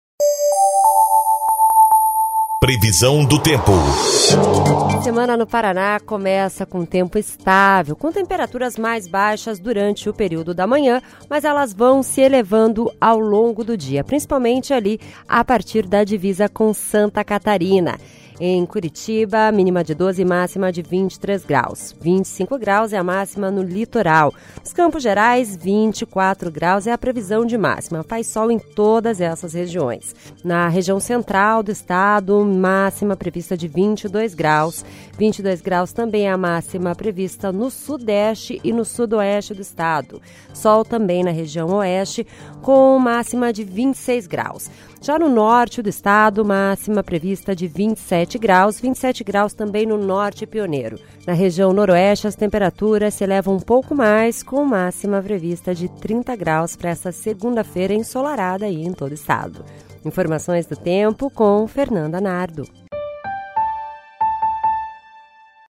Previsão do Tempo (21/08)